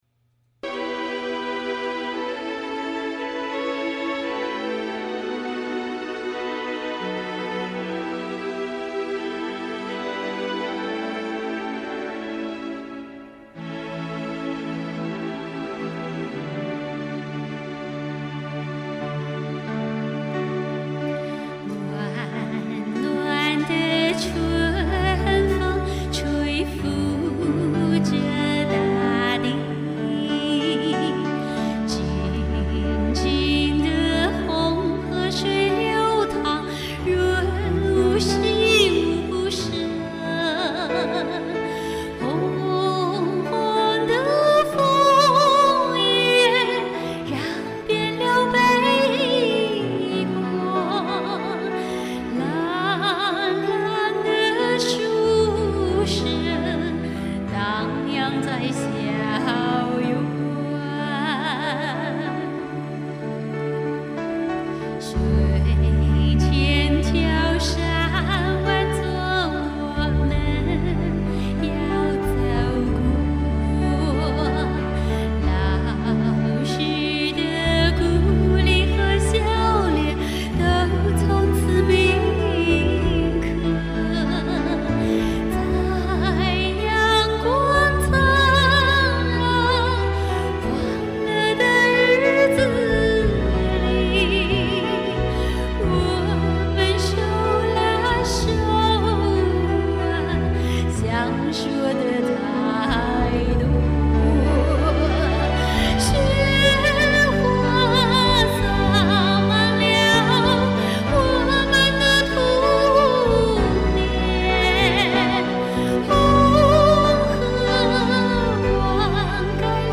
（泓河中文学校校歌）
孟卫东曲
泓河中文学校教师填词
独 唱  合 唱
a_common_dream_solo.mp3